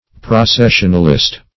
Search Result for " processionalist" : The Collaborative International Dictionary of English v.0.48: Processionalist \Pro*ces"sion*al*ist\, n. One who goes or marches in a procession.